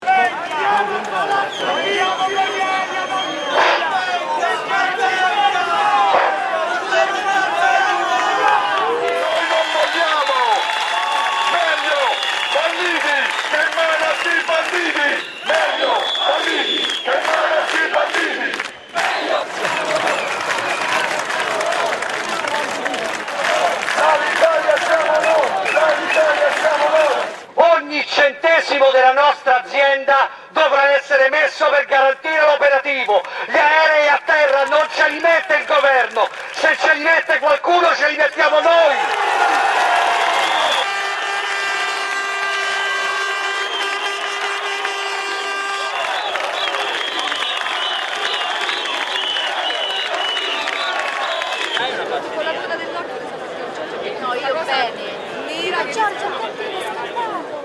Ambiances sonores foules 3
AMBIANCES SONORES DE FOULES